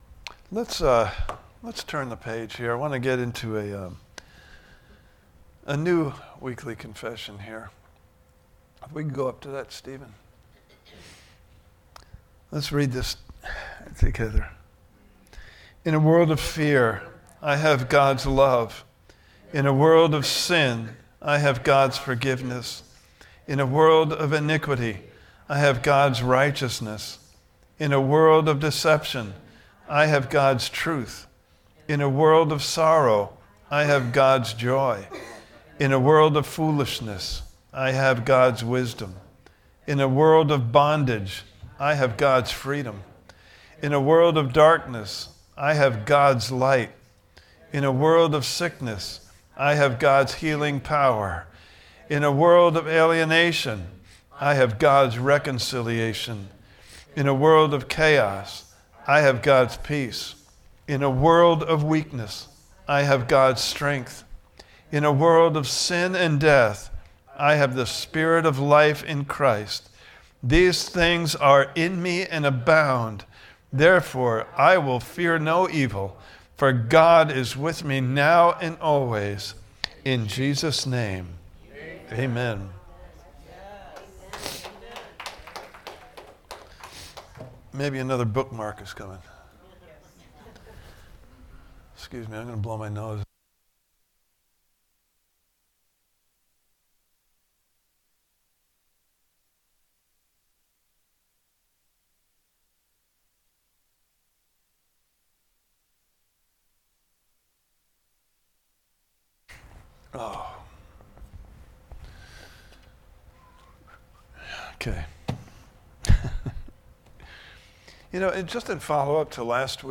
Prepared Service Type: Sunday Morning Service « Part 2